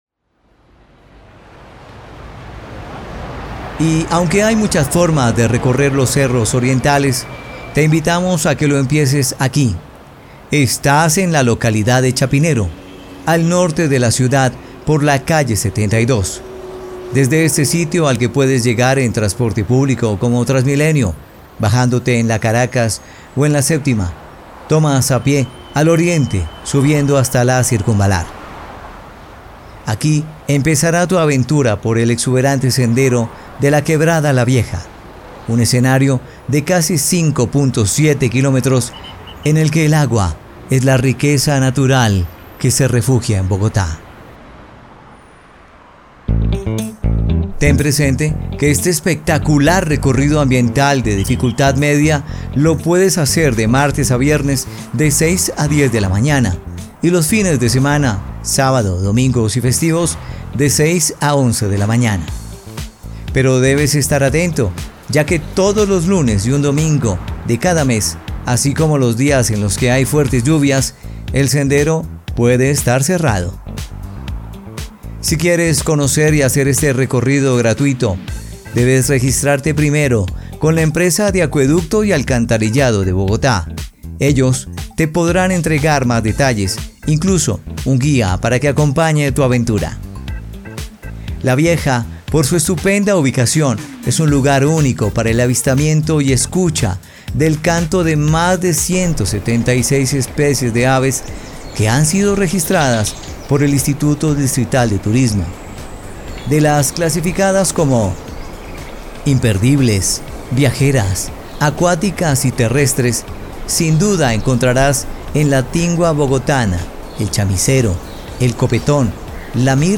Imagen principal de la audioguía